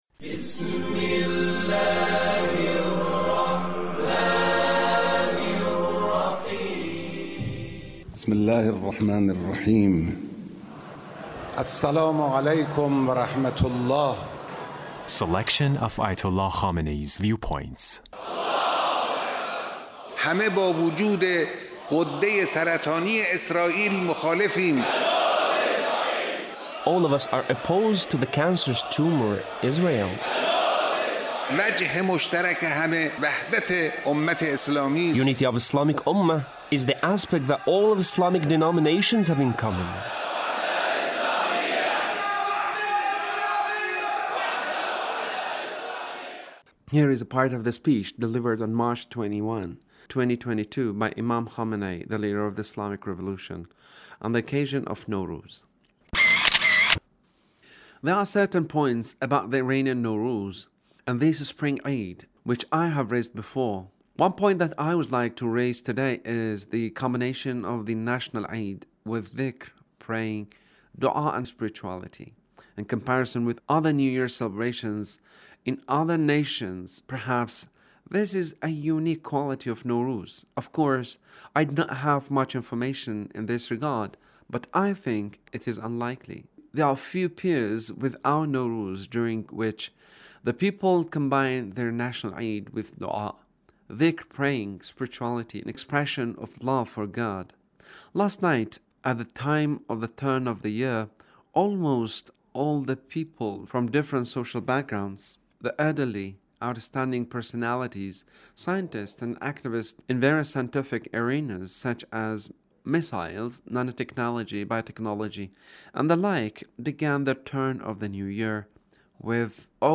Leader's Speech on Tree Planting Day